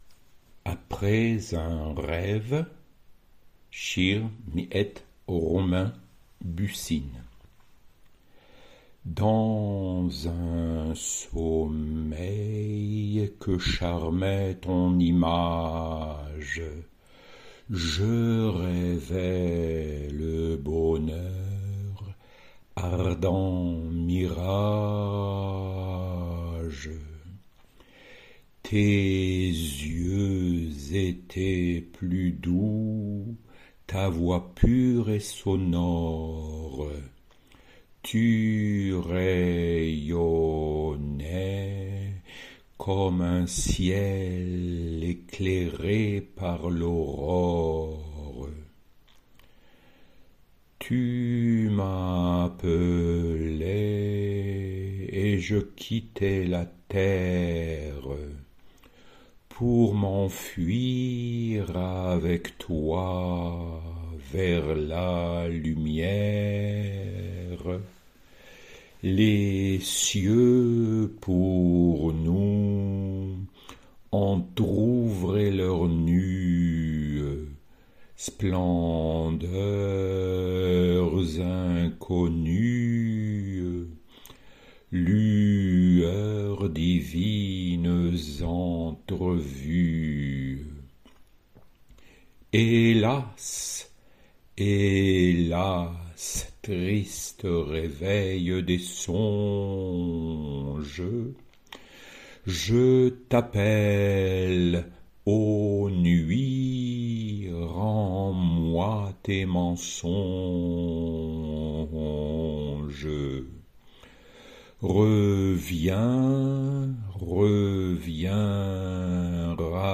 Pronunciation
apres_un_reve_pron.mp3